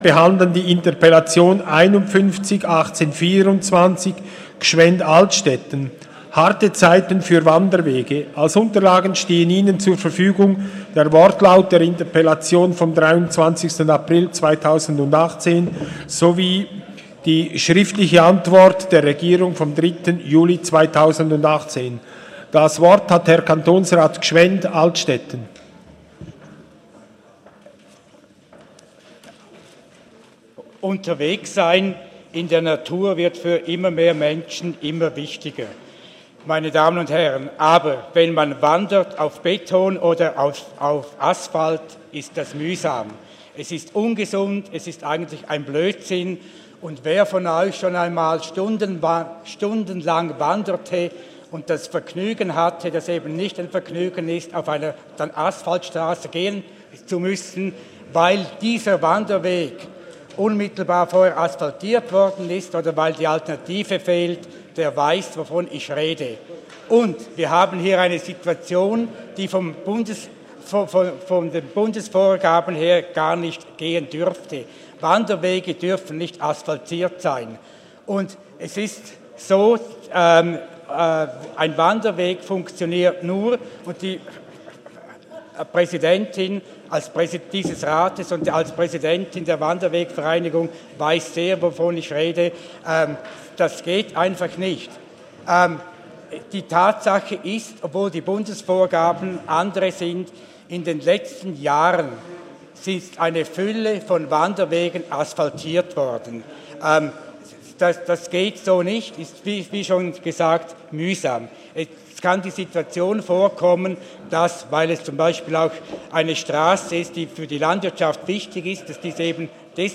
27.11.2018Wortmeldung
Session des Kantonsrates vom 26. bis 28. November 2018